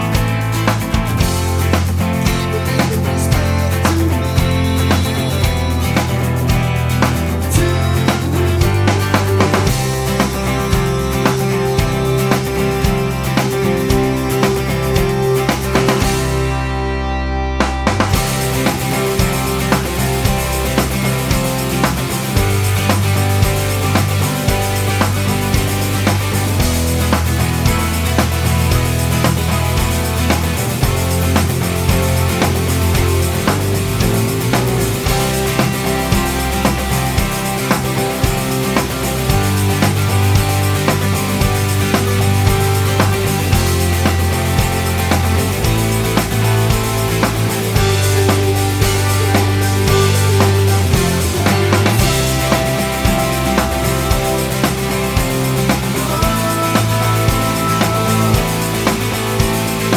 no Backing Vocals Indie / Alternative 3:23 Buy £1.50